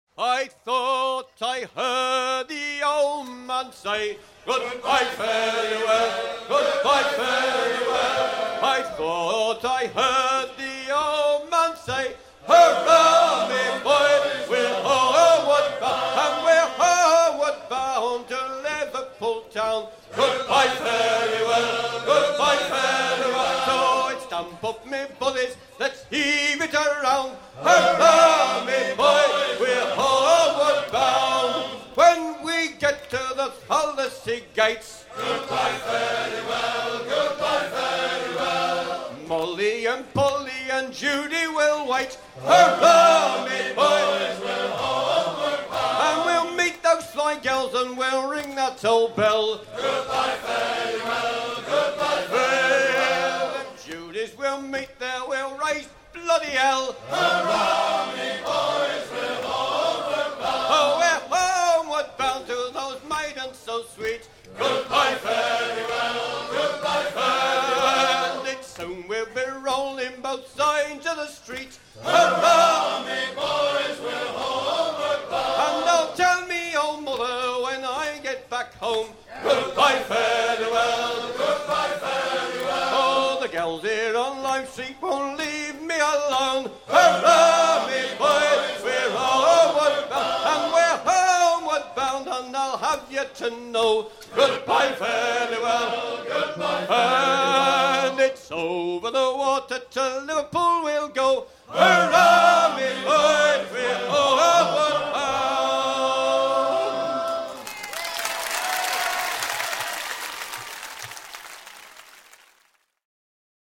à virer au cabestan
maritimes
Genre laisse
Pièce musicale éditée